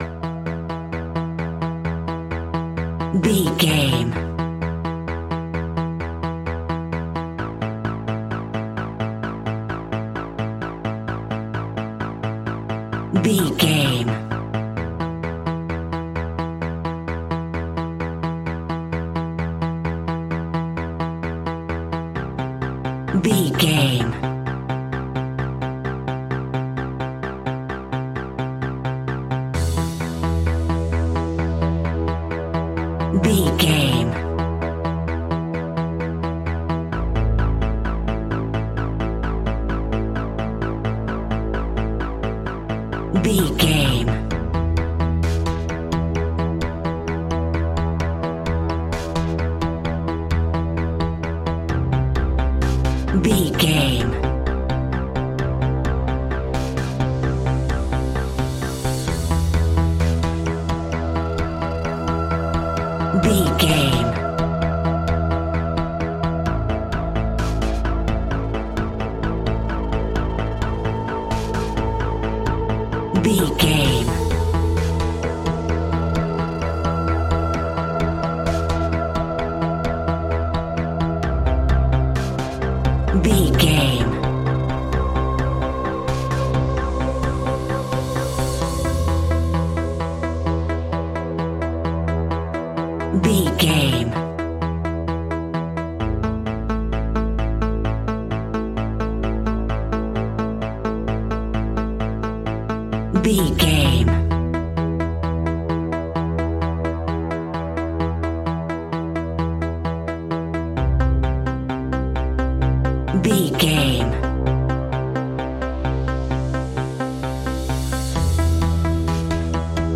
Aeolian/Minor
DOES THIS CLIP CONTAINS LYRICS OR HUMAN VOICE?
WHAT’S THE TEMPO OF THE CLIP?
ominous
dark
eerie
synthesiser
drums
ticking
electronic music